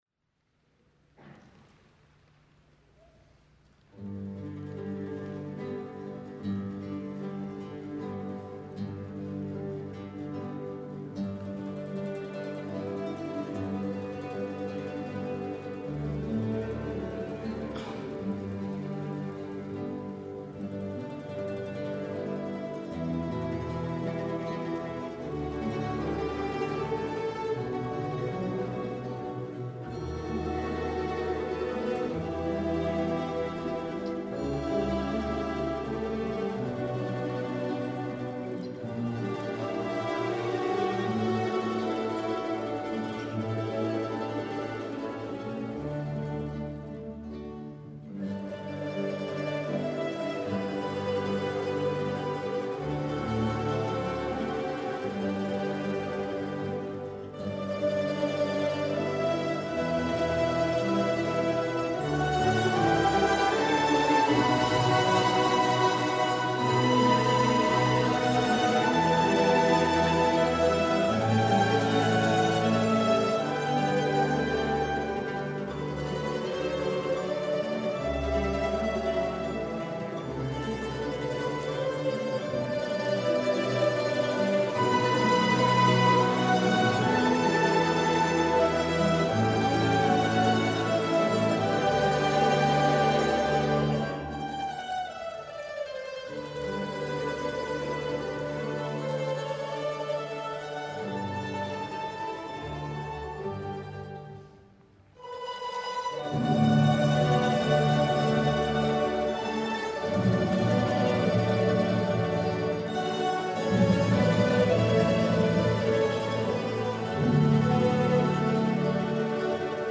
駒ヶ根マンドリーノ　第３1回定期演奏会
会　場　　　　　駒ヶ根市文化会館　大ホール